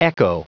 Prononciation du mot echo en anglais (fichier audio)
Prononciation du mot : echo